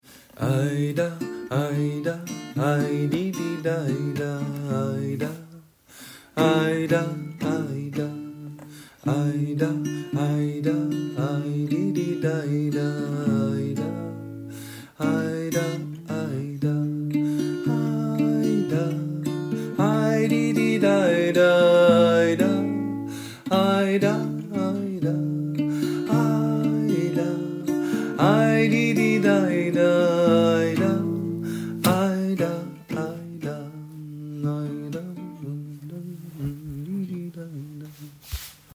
Voici quelques chants pratiqués en atelier.